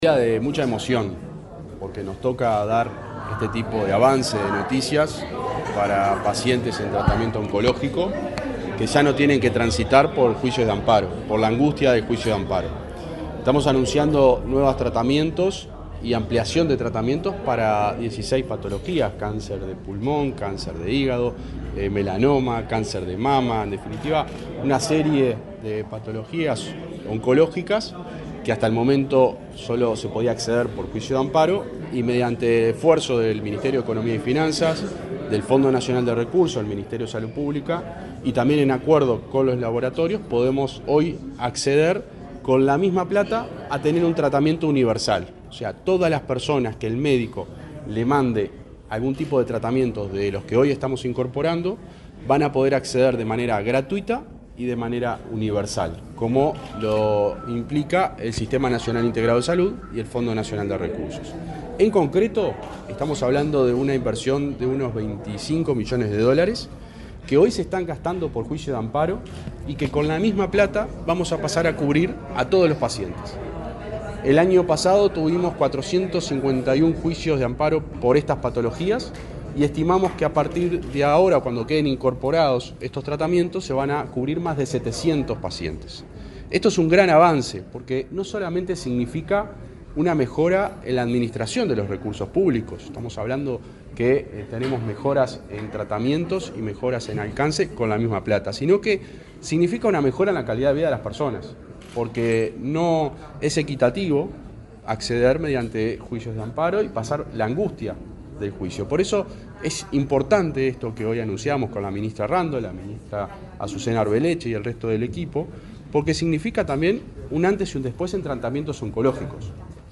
Declaraciones del subsecretario de Salud Pública
Declaraciones del subsecretario de Salud Pública 10/09/2024 Compartir Facebook X Copiar enlace WhatsApp LinkedIn El Ministerio de Salud Pública (MSP) anunció la incorporación de nuevos medicamentos a las prestaciones del Fondo Nacional de Recursos (FNR). Luego del acto, el subsecretario de Salud Pública, José Luis Satdjian, dialogó con la prensa.